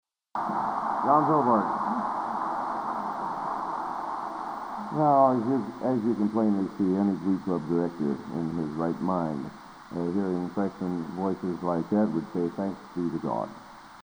Collection: Broadway Methodist, 1980
Genre: | Type: Director intros, emceeing